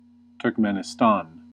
1. ^ /tɜːrkˈmɛnɪstæn/ turk-MEN-ih-stan or /ˌtɜːrkmɛnɪˈstɑːn/
En-us-Turkmenistan-2.ogg.mp3